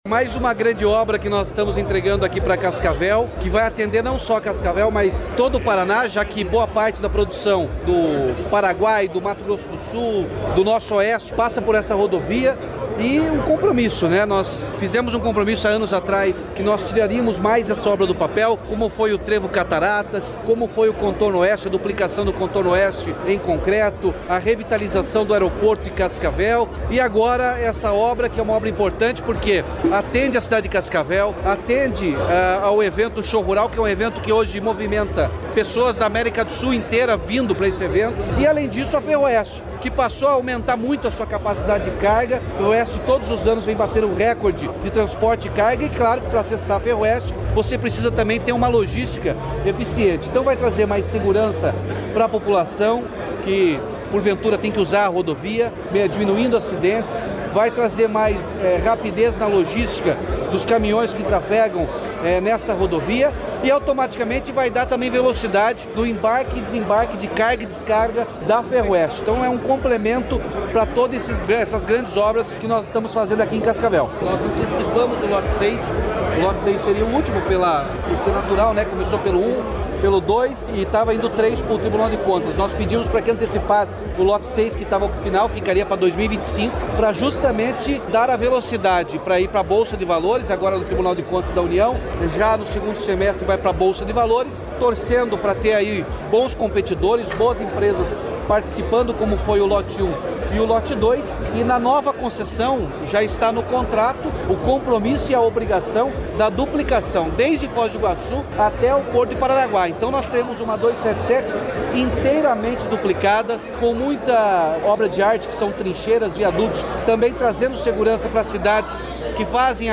Sonora do governador Ratinho Junior sobre a entrega da duplicação de 5,8 km da BR-277 em Cascavel